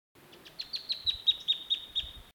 João-chaquenho
Tarphonomus certhioides
Chaco Earthcreeper